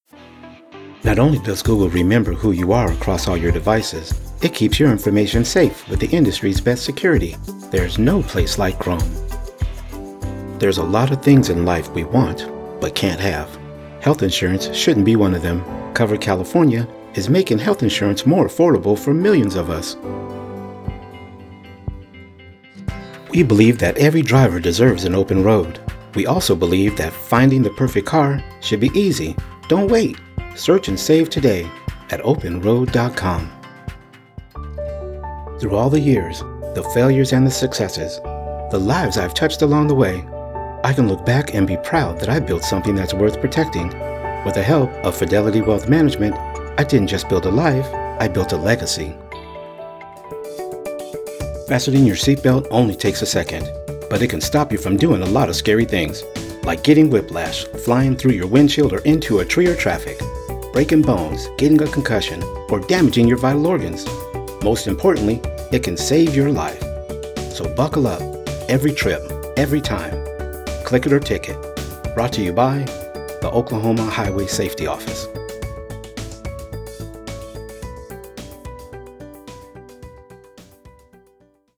Male
English (North American)
Adult (30-50), Older Sound (50+)
My voice possesses a rich, resonant depth that feels less like a sound and more like a physical presence. It carries the weathered texture of polished oak, grounded by a commanding low-end that remains effortlessly smooth. Even at a whisper, my delivery is steady and immersive, turning every sentence into a warm, cinematic experience.
A Mix Of Several Commercials